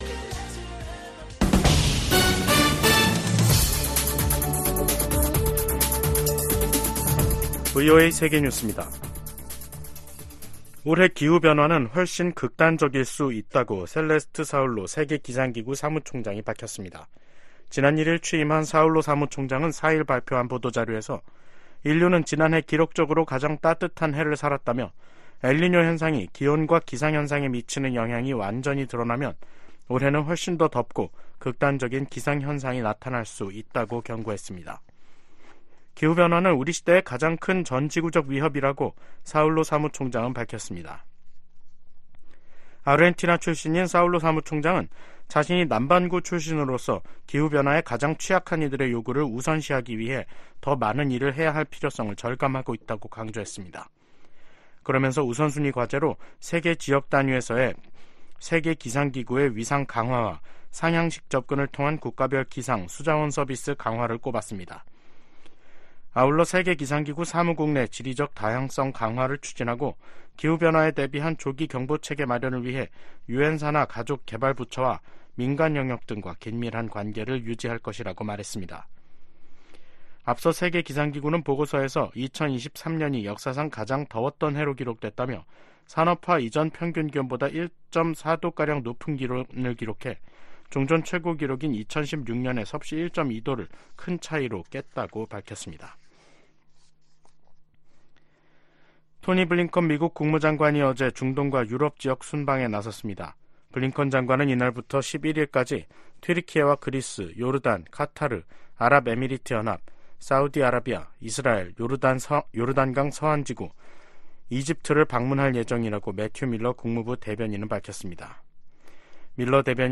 VOA 한국어 간판 뉴스 프로그램 '뉴스 투데이', 2024년 1월 5일 3부 방송입니다. 북한군이 5일 오전 9시부터 서북도서지역에서 해안포 200여발을 발사했습니다. 최근 예멘 후티 반군이 이스라엘을 향해 발사한 순항 미사일 파편에서 한글 표기가 발견됐습니다. 최근 러시아가 북한으로부터 탄도미사일을 제공받아 우크라이나 공격에 사용했다고 미국 백악관이 밝혔습니다.